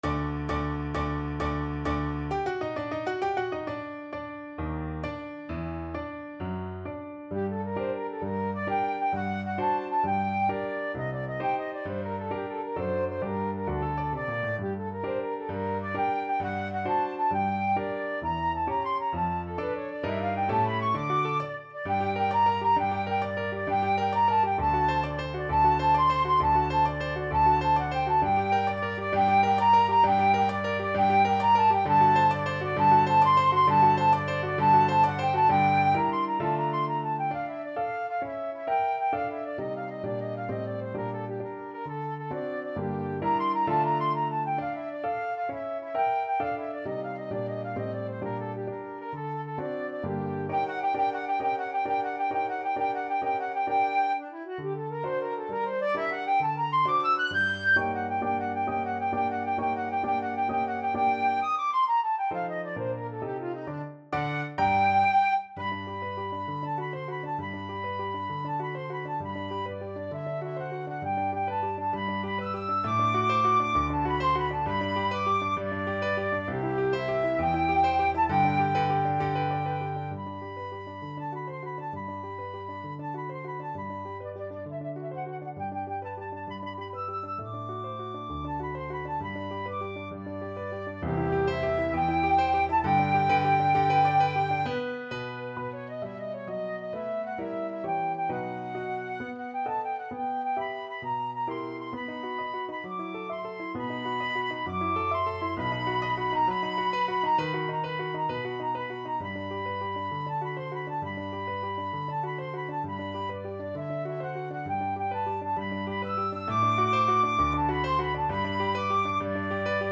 6/8 (View more 6/8 Music)
Classical (View more Classical Flute Music)